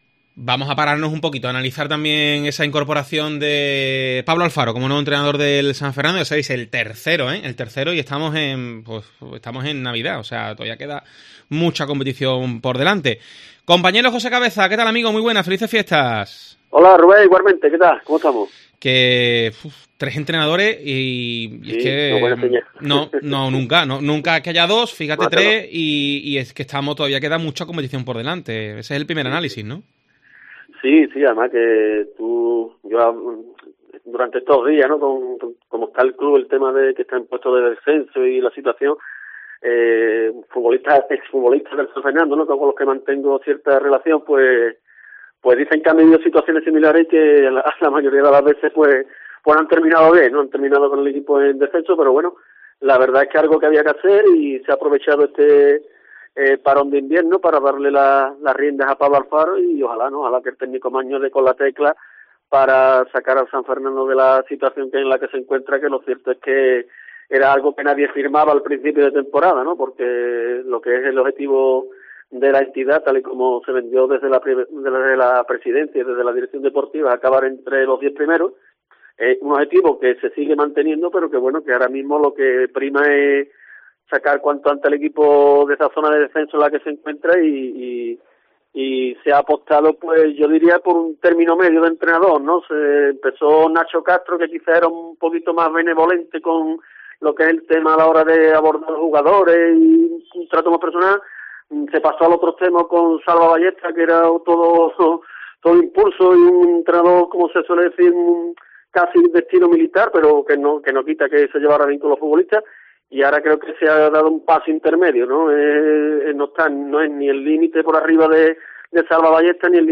Escucha el tiempo de opinión sobre la llegada del tercer entrenador esta temporada en el cuadro azulino